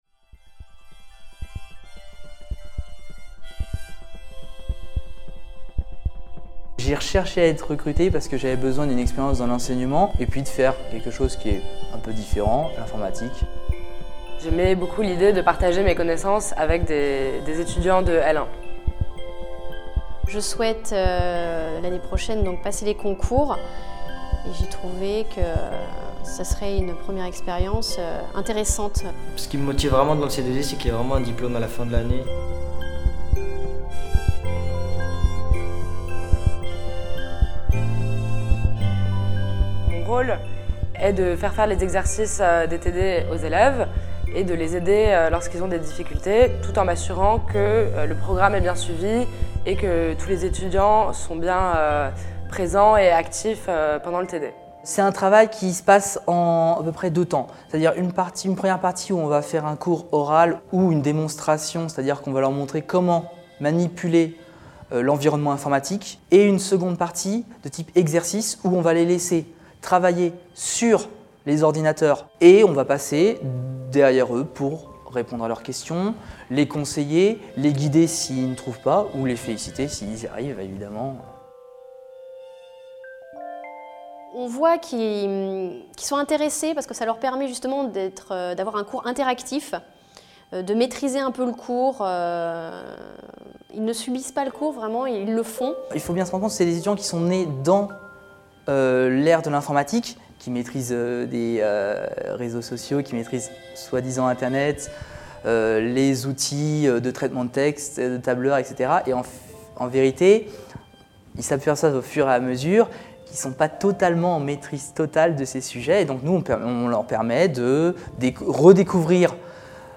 Des chargés de TD témoignent ici sur leur expérience d'une année de formation C2i. Ils expliquent l'expérience qu'elle leur apporte et les avantages de cette formation pour les étudiants. Des étudiants apportent aussi leur témoignage.